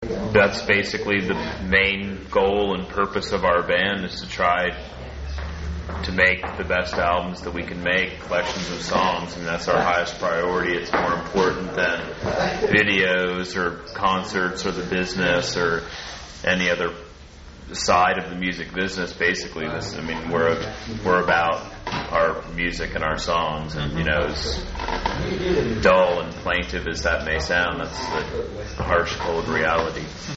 Intervju: PAVEMENT